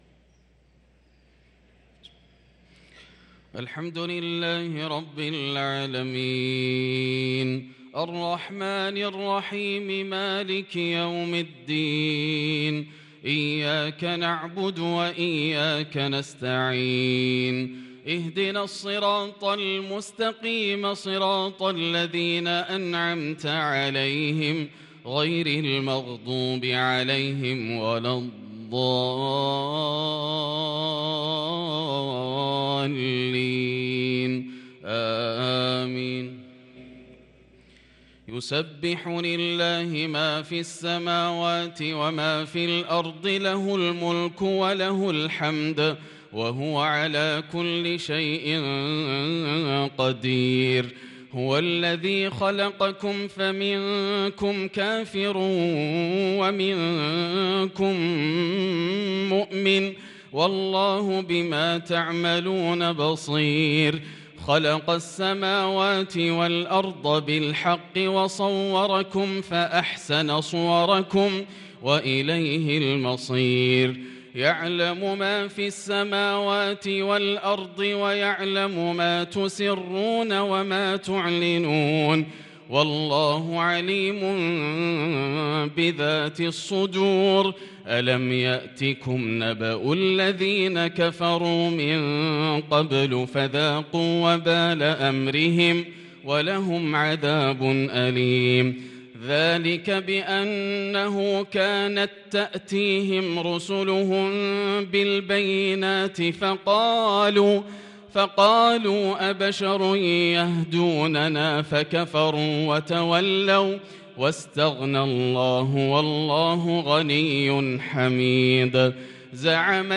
صلاة العشاء للقارئ ياسر الدوسري 27 شعبان 1443 هـ
تِلَاوَات الْحَرَمَيْن .